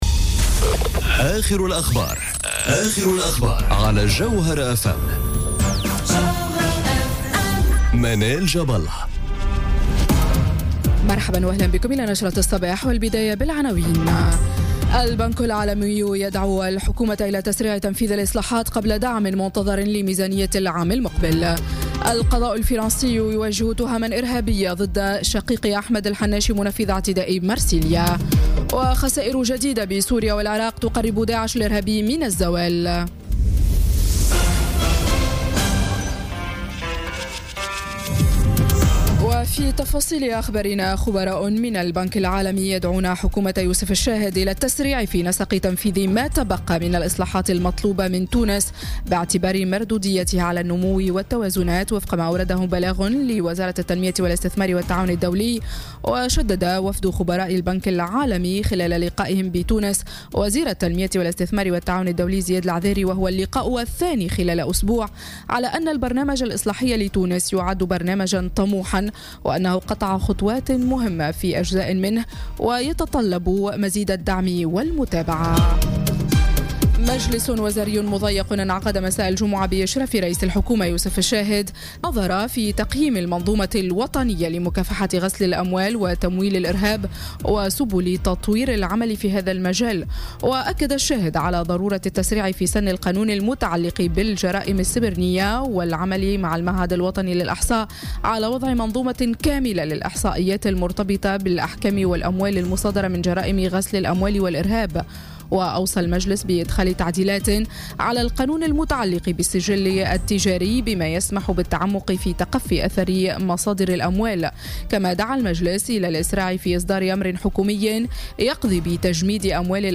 نشرة أخبار السابعة صباحا ليوم السبت 04 نوفمبر 2017